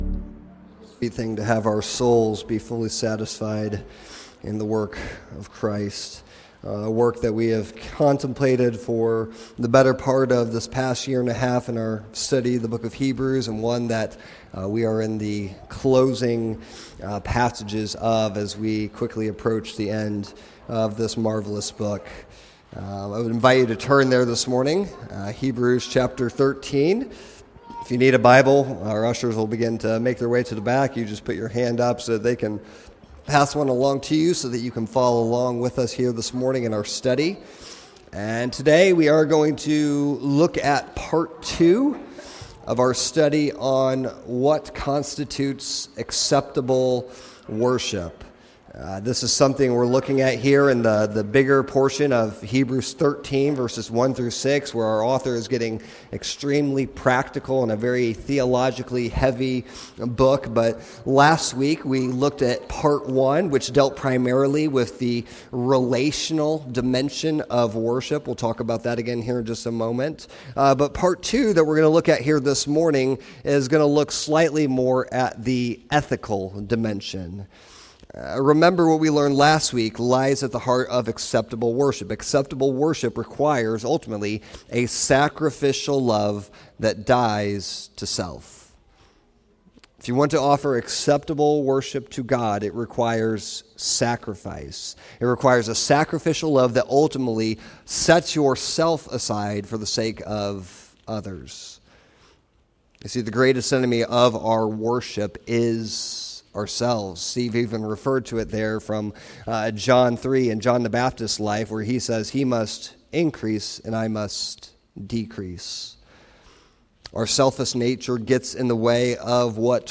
Hebrews: Steadfast Faith in Our Superior Savior Old Testament Saints: Faith that Triumphs & Suffers September 7, 2025 Sermons Passage: Hebrews 11:32-40 The Old Testament saints remind us that faith is often met with both great triumph and great suffering.